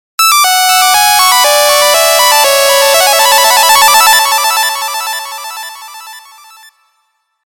В стиле Электро